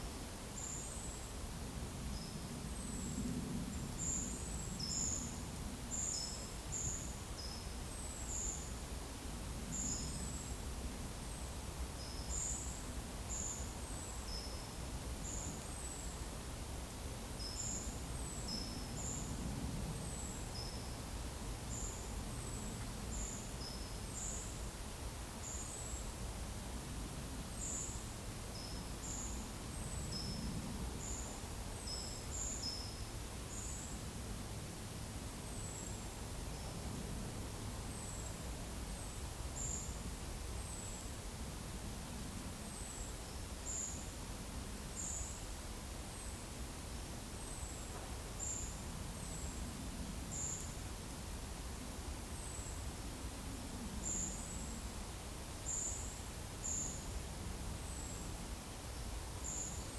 PEPR FORESTT - Monitor PAM - Renecofor
Turdus iliacus
Certhia familiaris
Certhia brachydactyla
Turdus merula
Erithacus rubecula